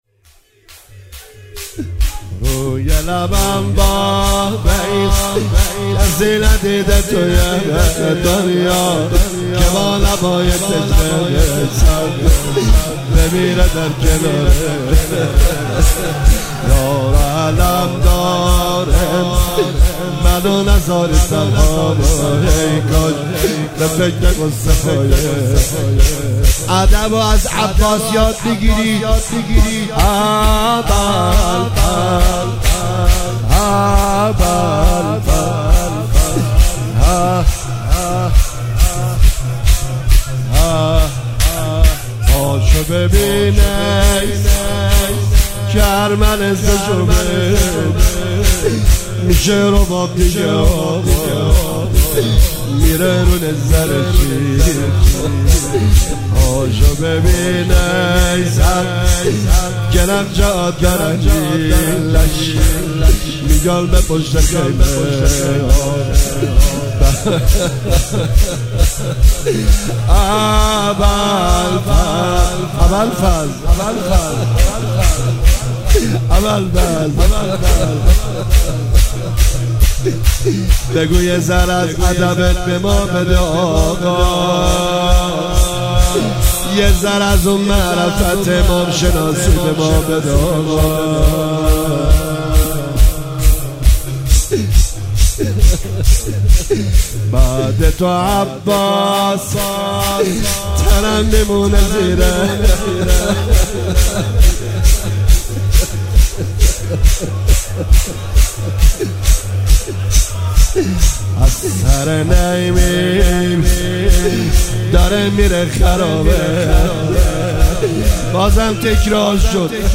مداحی شور